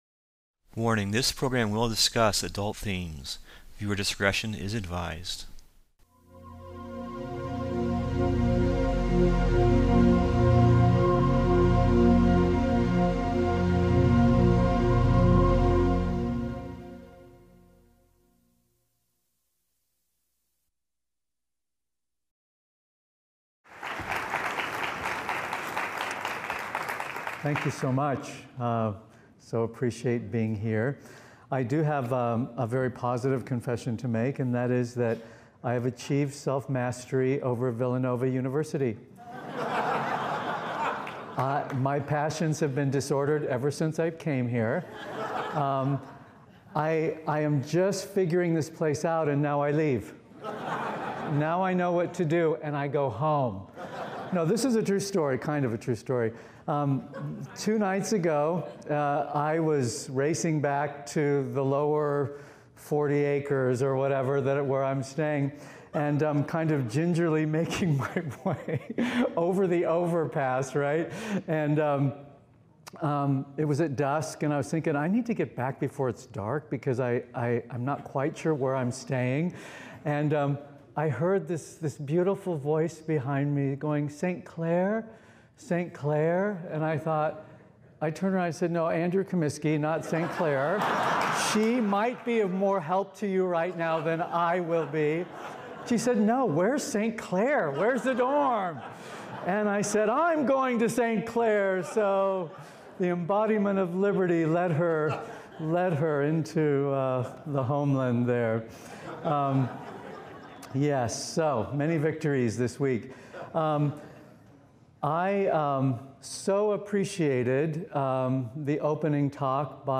>>> Play Adult themes Discussed For the 2014 Courage Conference in Philadelphia at Villanova University